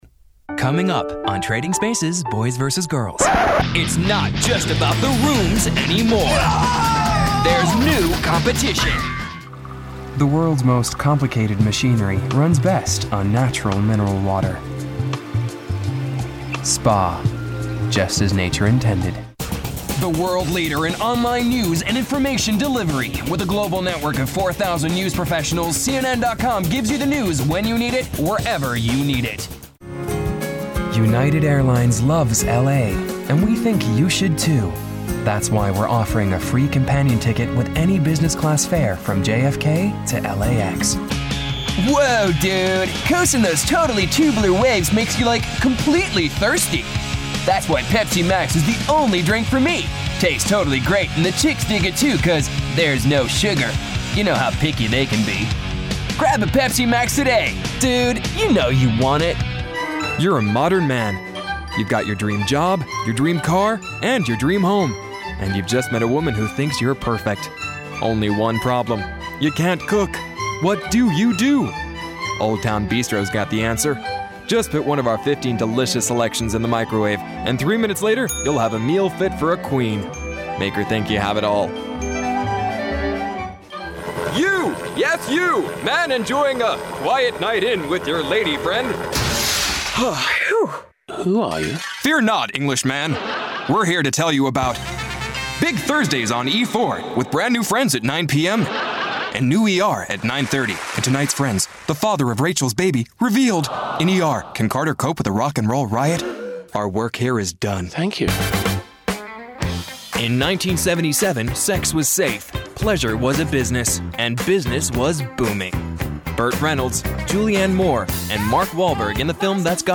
Norwegian / Bokmal. Actor, youthful, experienced, versatile.
Norwegian and English Promos and Commercials